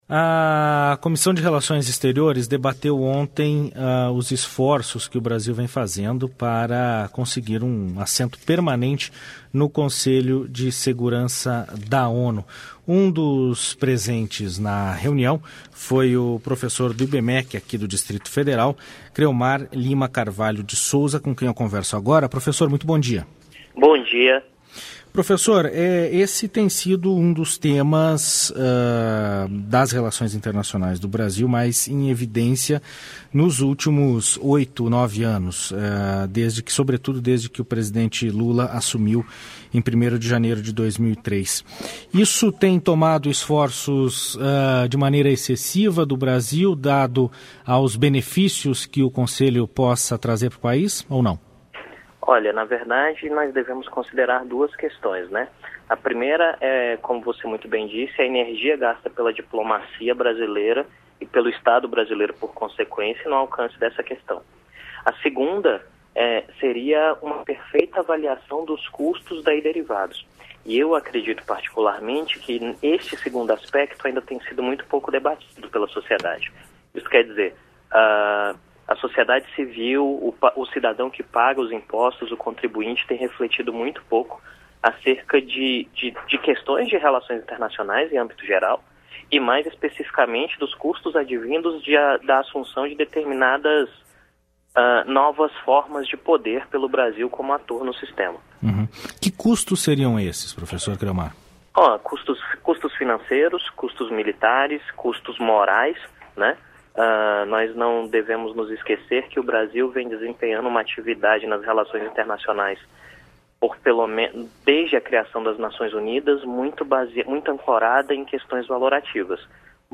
Programa diário com reportagens, entrevistas e prestação de serviços
Vaga no Conselho da ONU deve ser debatida com sociedade, diz professor